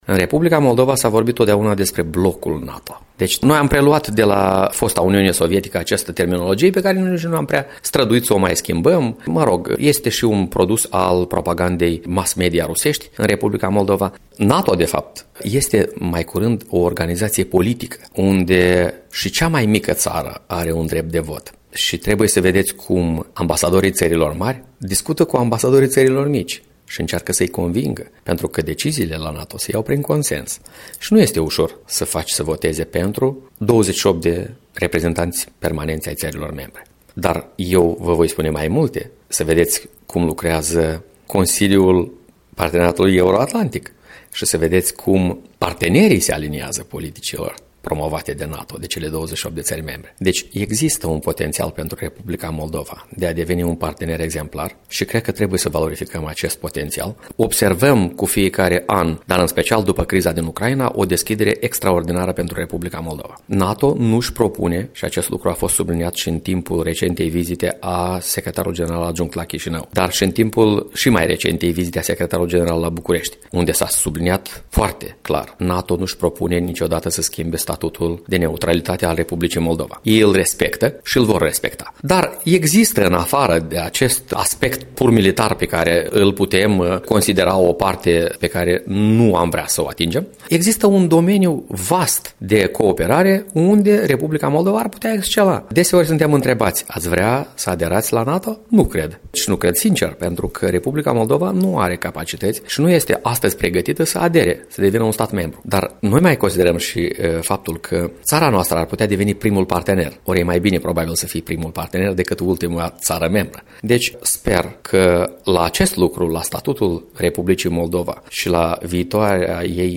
Interviu cu adjunctul ambasadorului R. Moldova în Belgia, Andrei Dragancea